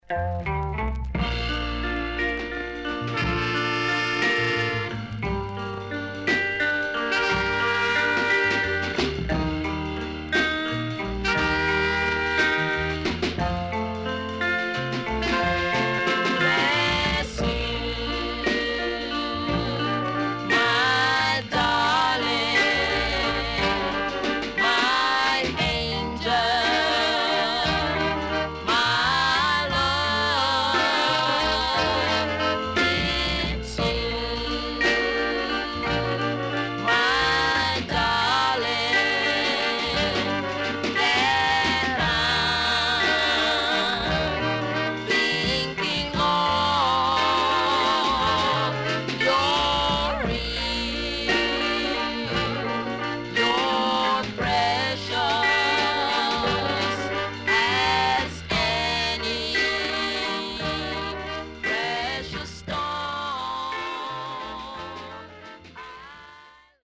Nice Ska Inst
SIDE A:所々チリノイズ、プチノイズ入ります。